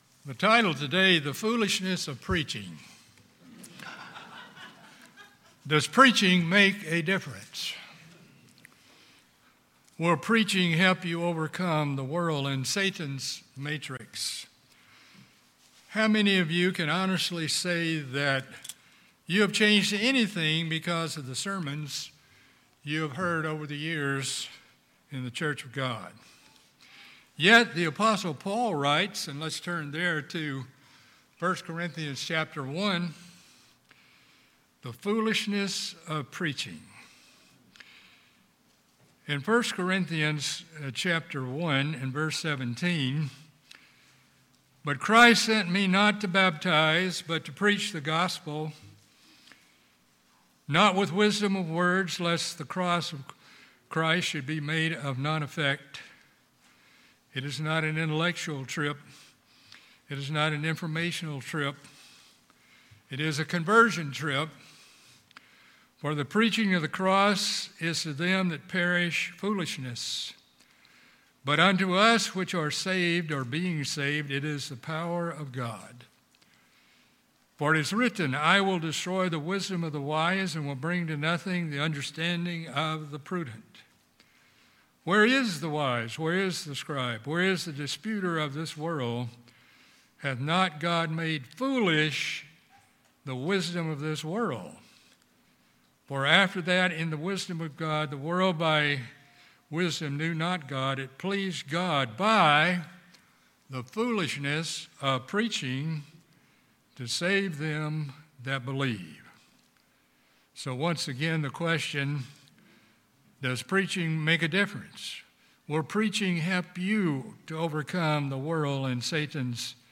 In this sermon we pose the question do we take the sermons we hear to heart and make a conscious and concerted effort to change based on hearing the word of God. We explore reasons why people find it so difficult to change and furthermore we note what we need to do in view of what we are facing in dealing with Satan's matrix.